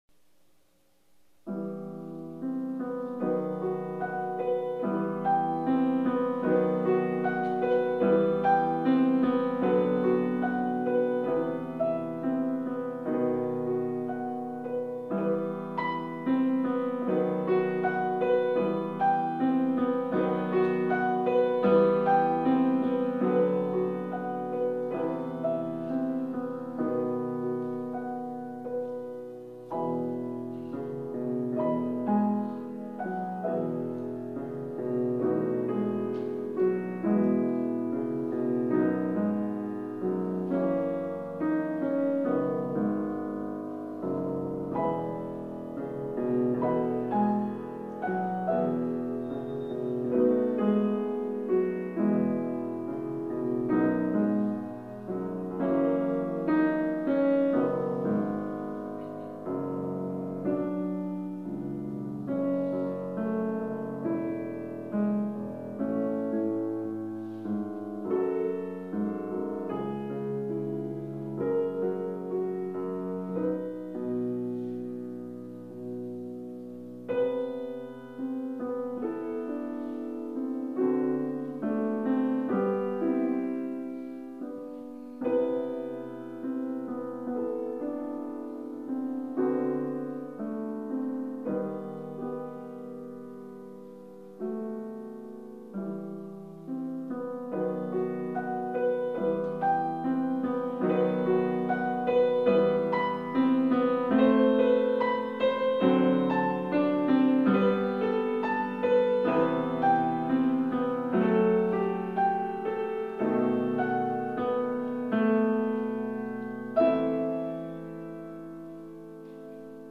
Here's some of my piano recordings.